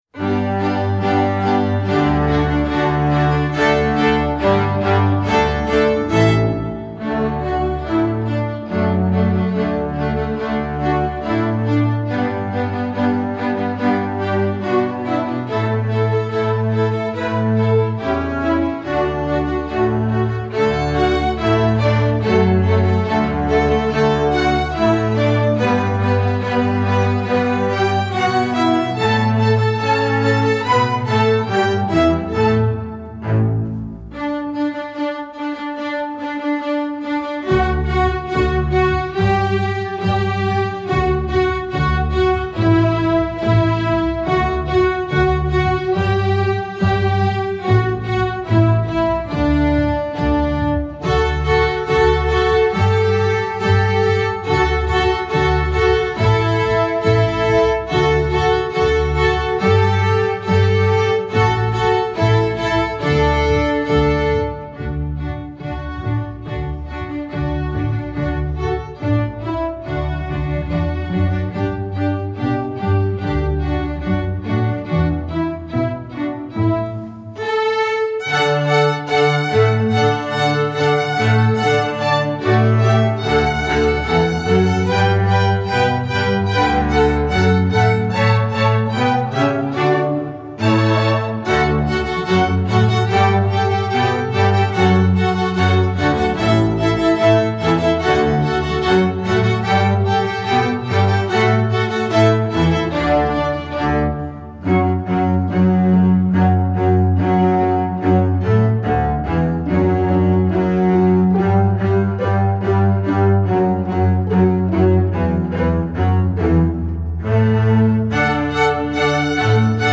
Vocal Duets/Other Sacred/Spiritual Duets